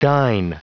Prononciation du mot dine en anglais (fichier audio)
Prononciation du mot : dine